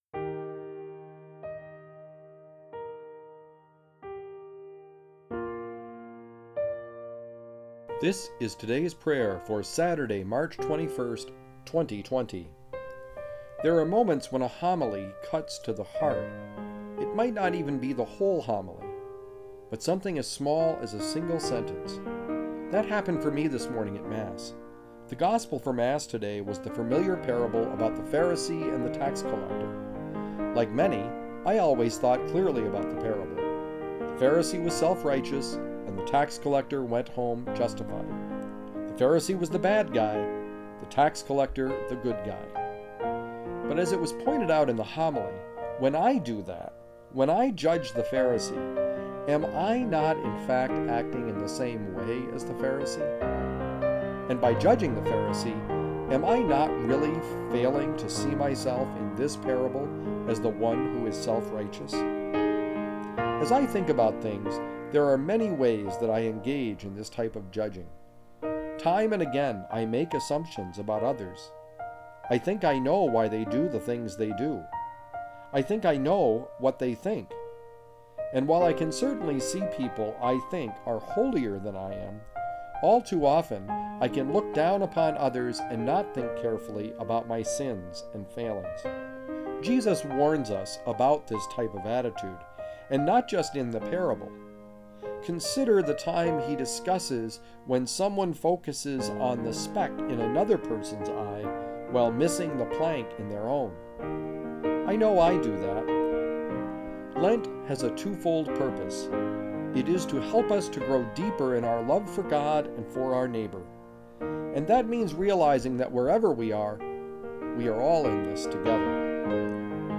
Music: Cheezy Piano Medley by Alexander Nakarada